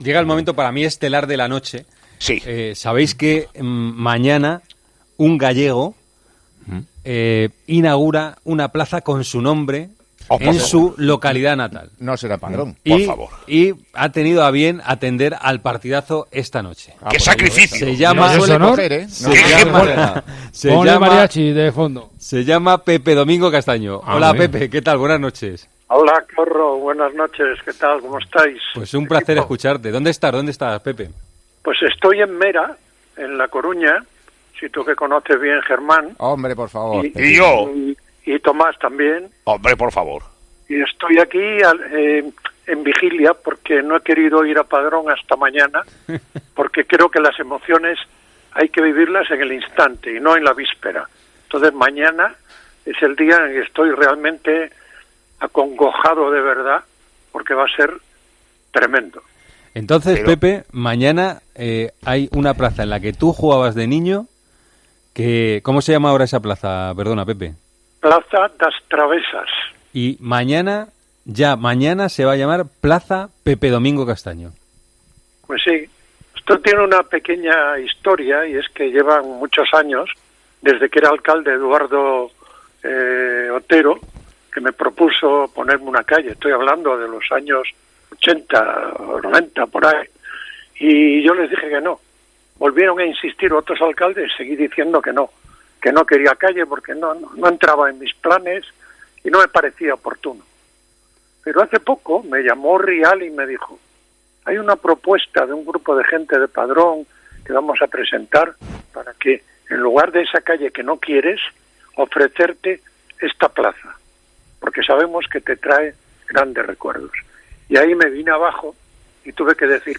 En la noche antes de ese momento tan icónico, 'El Partidazo de COPE' habló con él antes de un momento tan bonito y merecido en su carrera.
"Me pilláis en Mera, en La Coruña. Como estoy en vigilia, no he querido ir a Padrón hasta mañana sábado, porque las emociones hay que vivirlas en el instante y no en la víspera. Estoy acongojado con esto, va a ser tremendo", señaló Castaño a José Luis Corrochano.